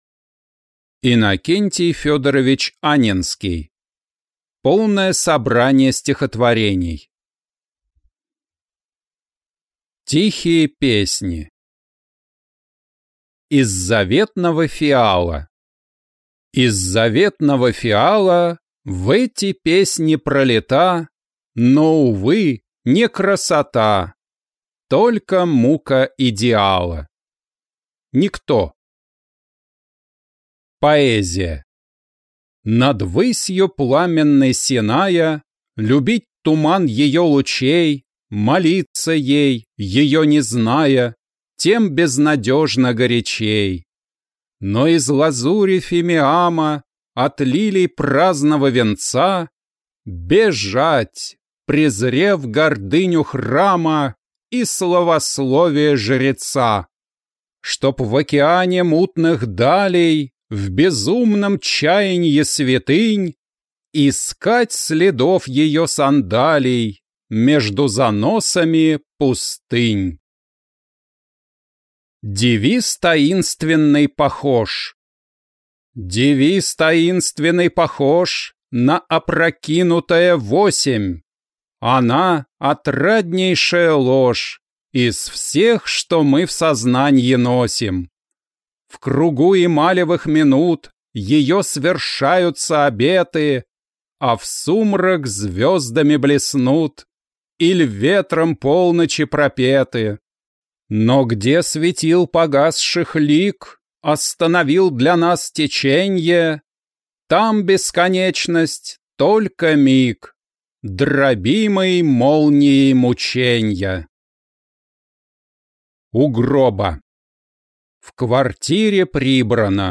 Аудиокнига Полное собрание стихотворений | Библиотека аудиокниг